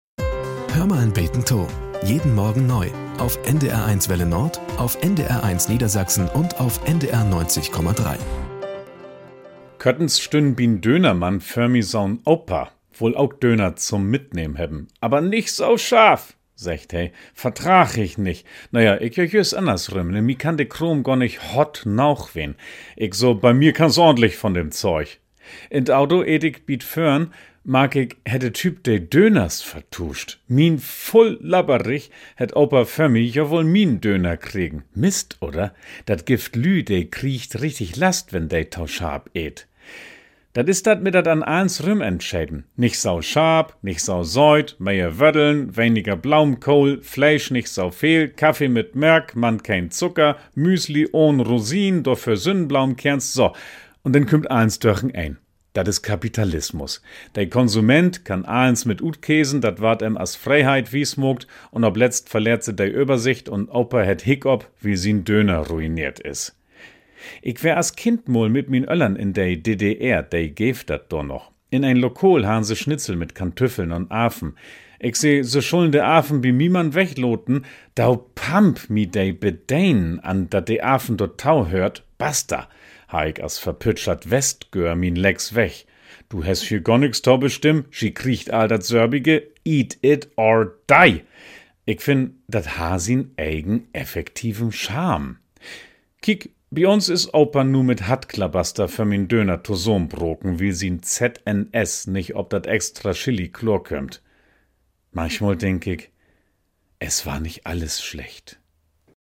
Nachrichten - 15.06.2023